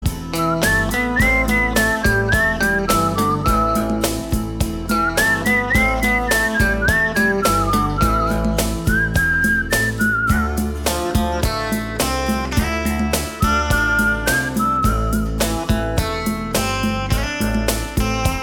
• Качество: 192, Stereo
свист
без слов
инструментальные
электрогитара